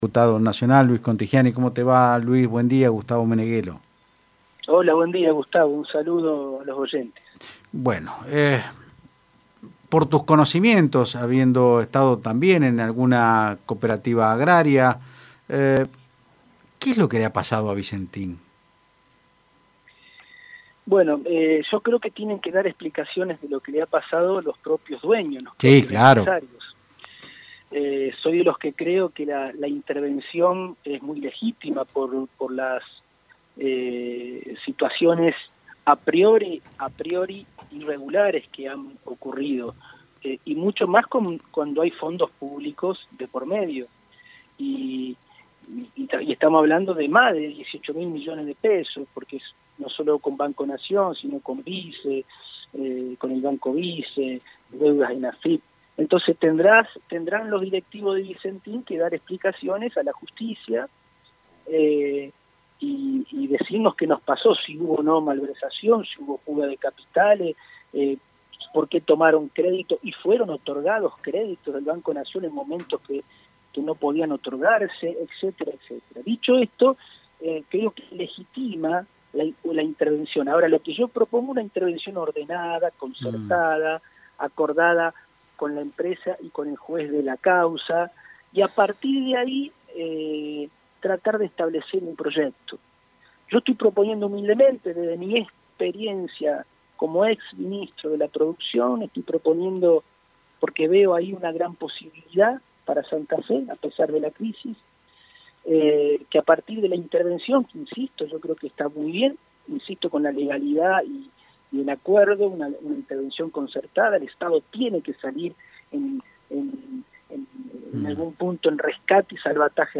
El Diputado Nacional Luis Contigiani explicó en Otros Ámbitos (Del Plata Rosario 93.5) el proyecto alternativo que propone un modelo de articulación público privada, para convertir a la firma en una corporación agroindustrial exportadora santafesina.